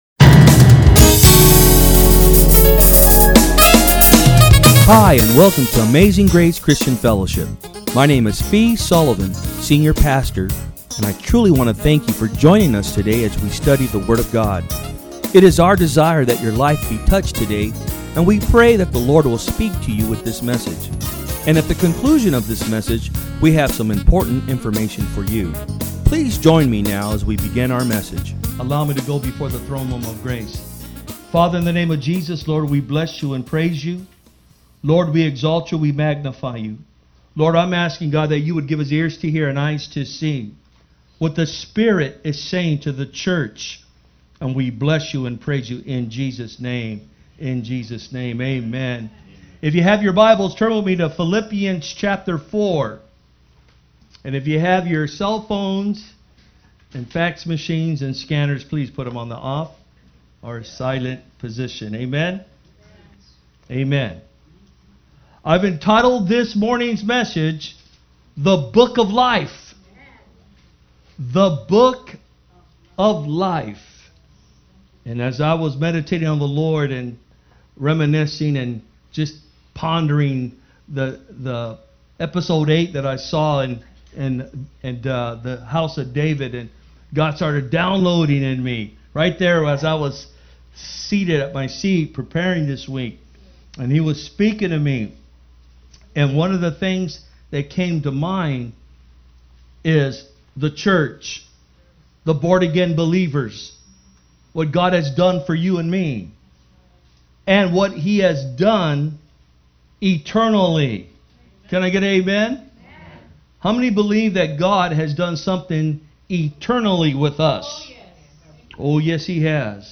Message
From Service: "Sunday Am"